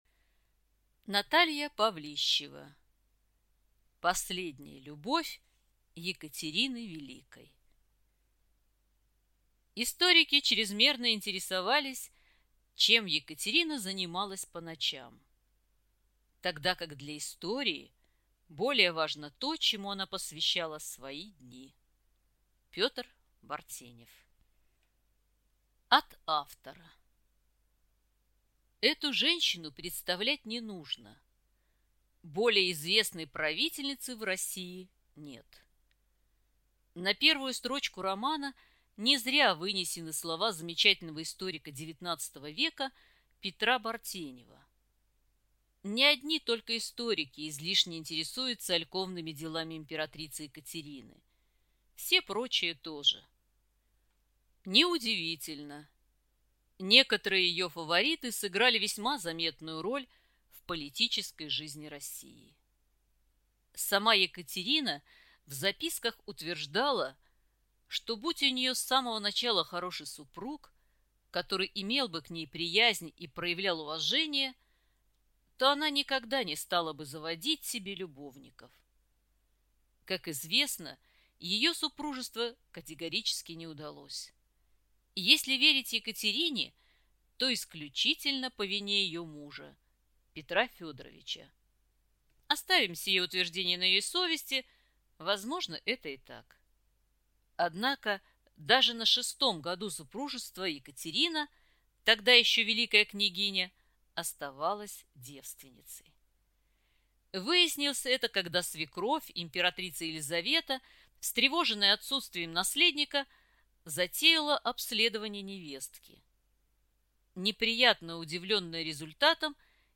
Аудиокнига Последняя любовь Екатерины Великой | Библиотека аудиокниг